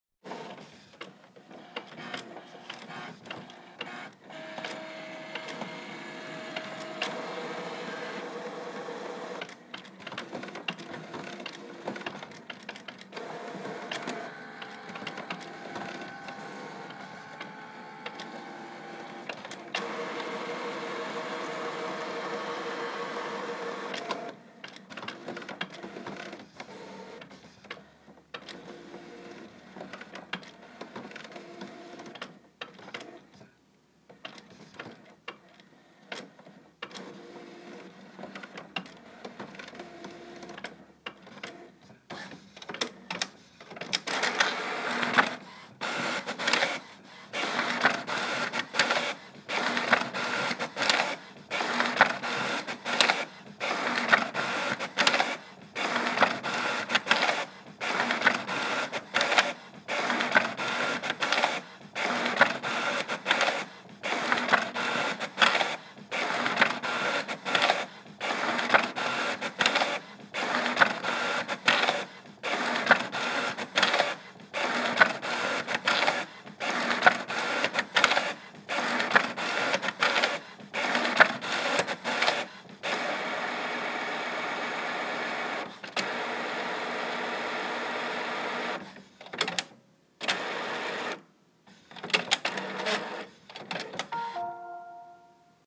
making-20-copies-of-a-blank-page.m4a